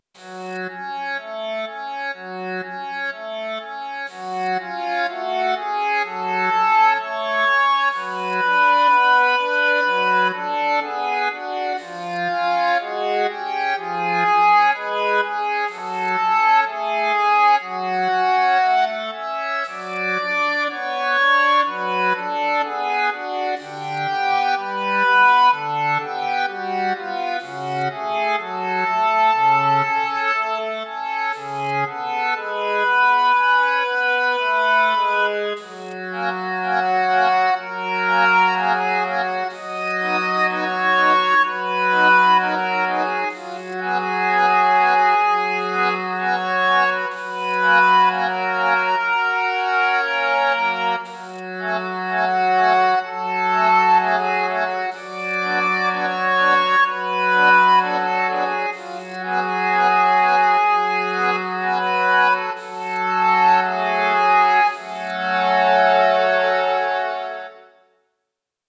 -oggをループ化-   オルガン 不安 1:12 mp3